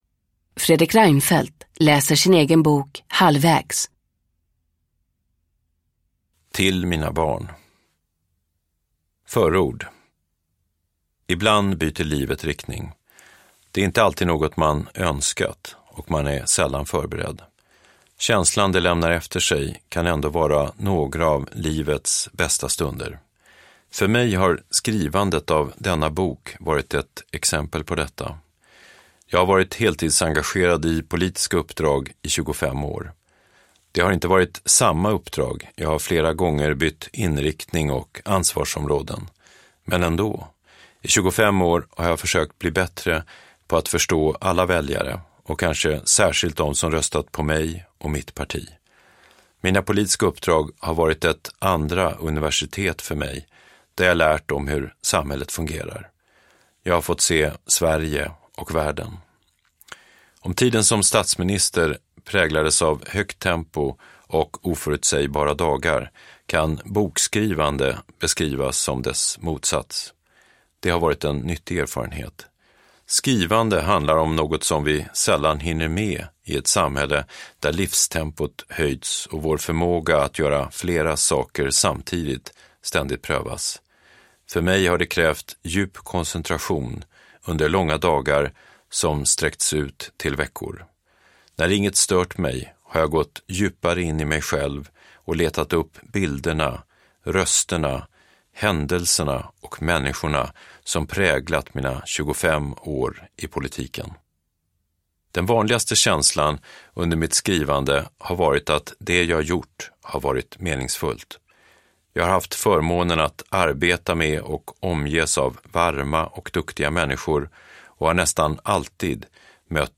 Uppläsare: Fredrik Reinfeldt
Ljudbok